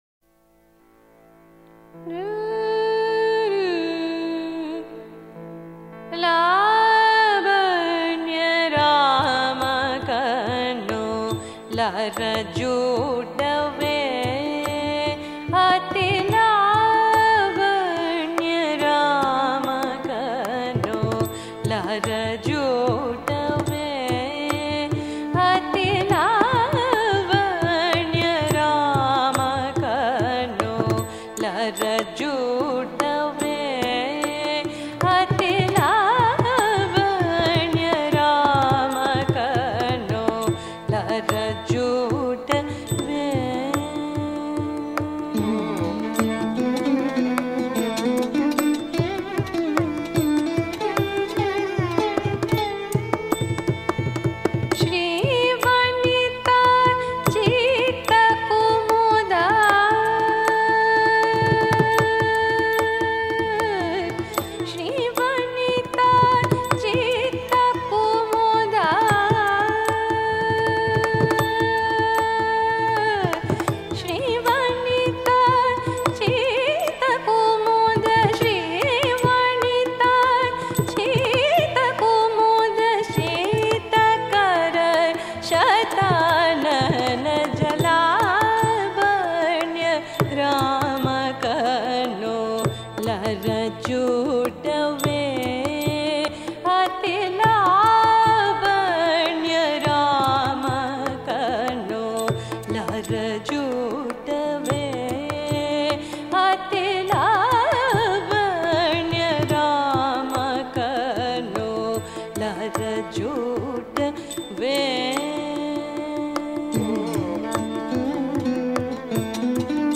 সুর ও তাল: রাগ-পূর্ণষড়জ। তাল-একতাল। রাগ: পূর্ণষড়্‌জ রাগিণী (মহীশূর)।